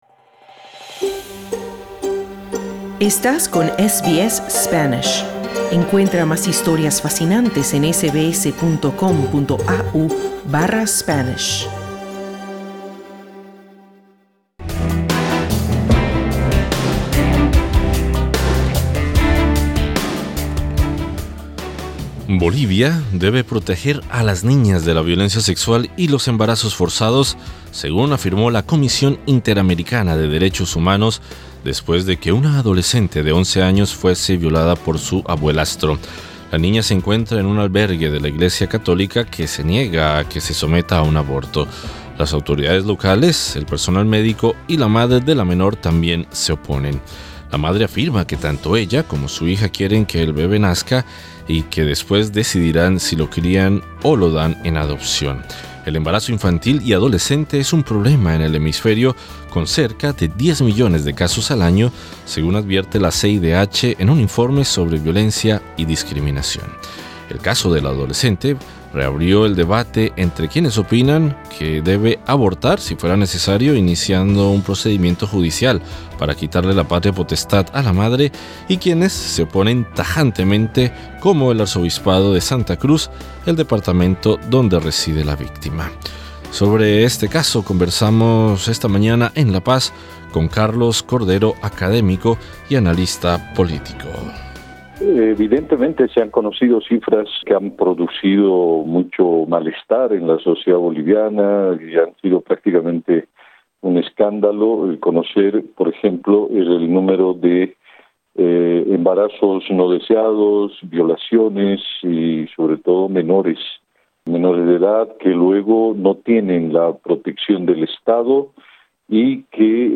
académico y analista político. Escucha la entrevista haciendo clic en la imagen de portada.